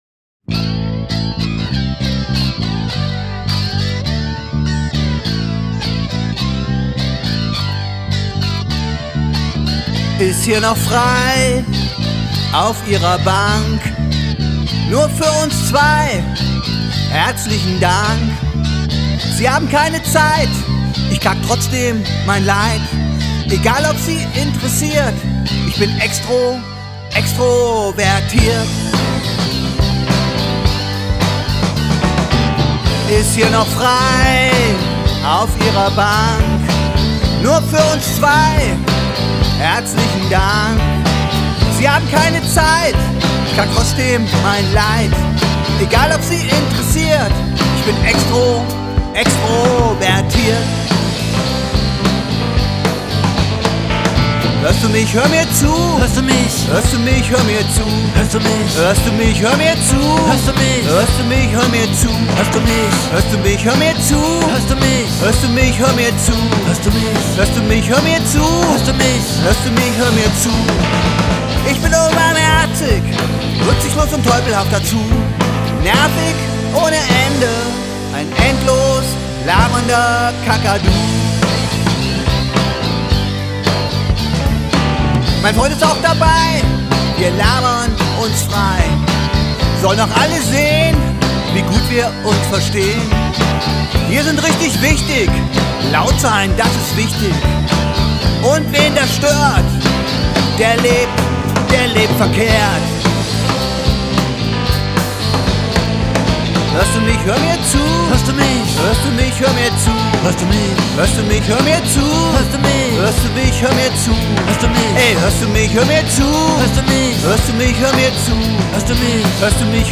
IM STUDIO IN KASSEL GEWESEN
UNSER TITELSONG